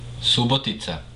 Subotica (Serbian: Суботица, pronounced [sǔbotitsa]
Sr-Subotica.oga.mp3